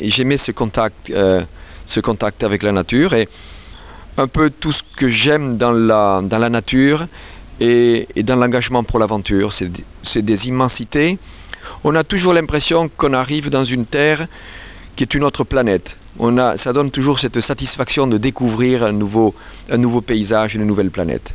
Veu de Jean-Louis 115 KBytes.